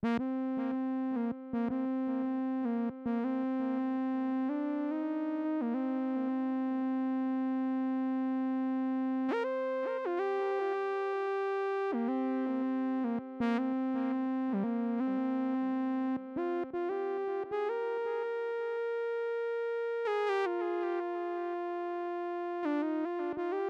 11 lead A.wav